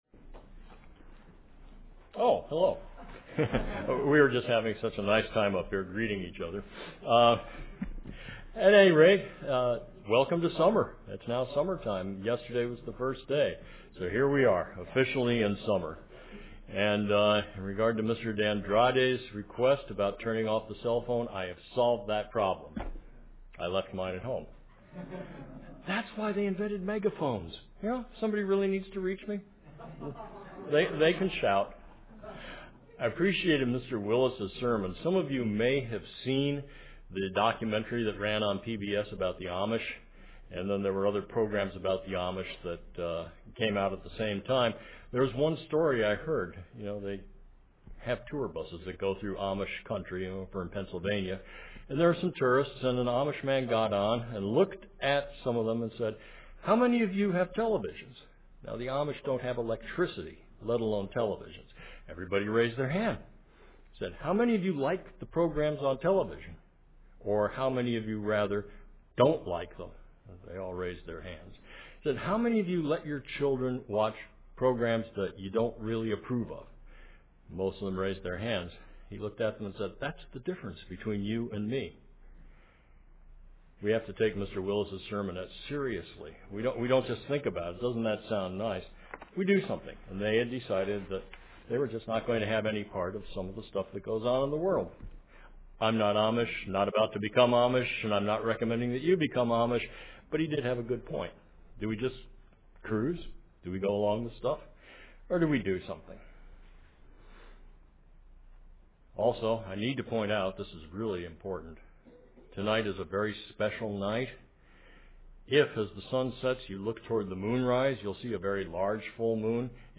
Given in San Francisco Bay Area, CA